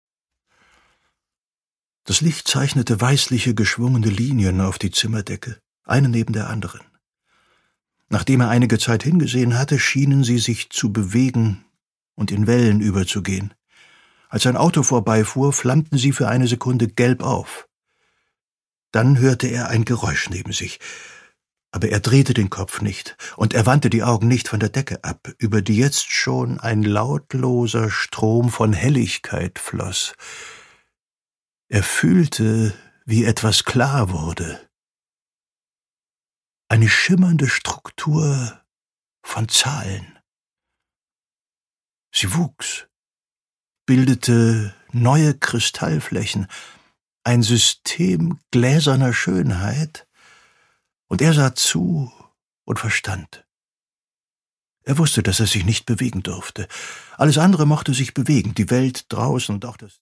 Produkttyp: Hörbuch-Download
Gelesen von: Ulrich Noethen